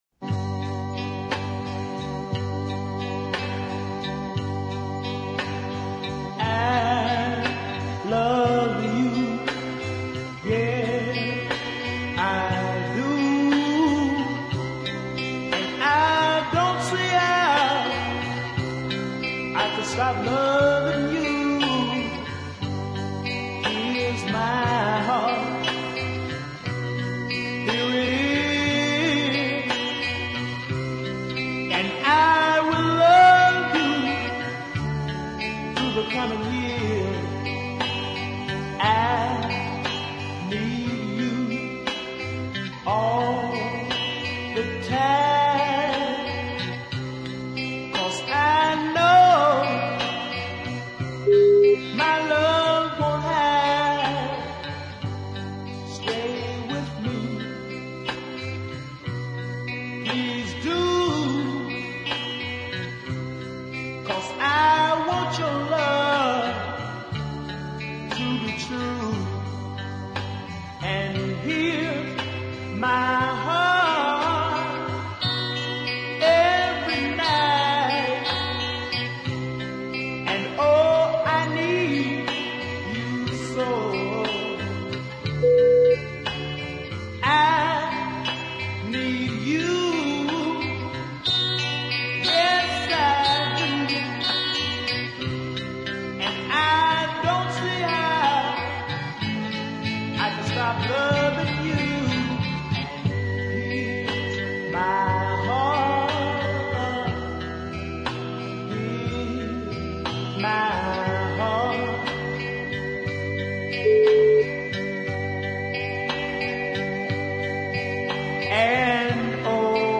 it has a much simpler form